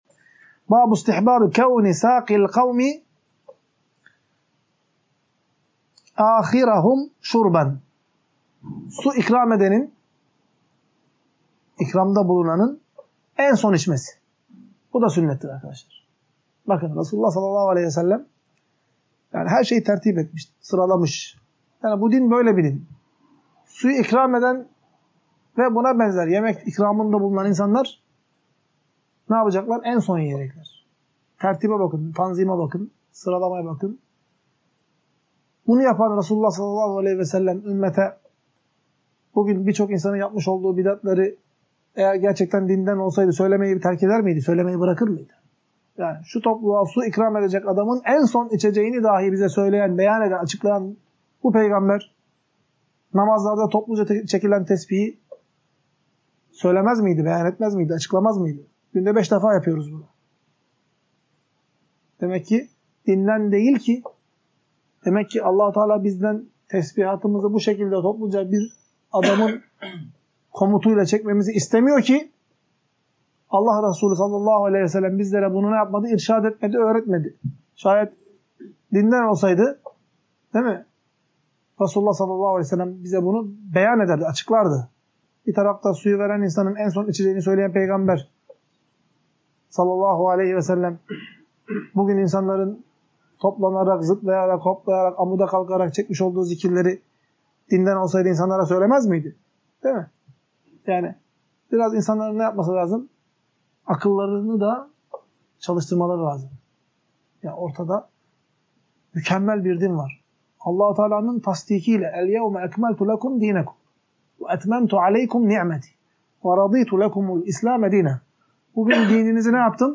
Ders - 16.